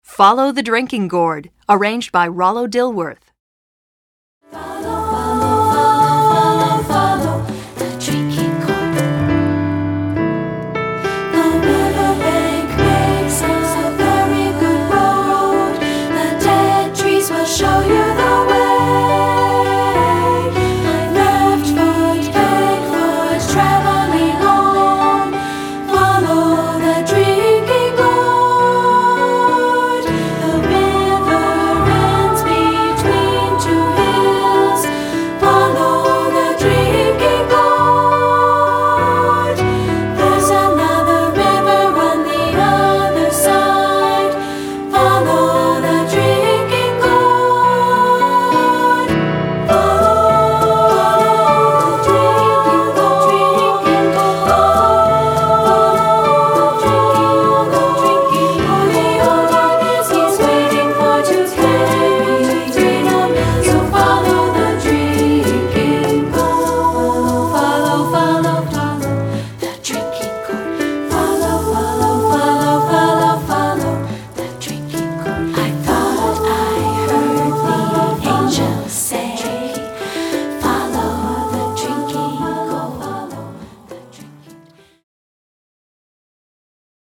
Composer: Spirituals
Voicing: 2-Part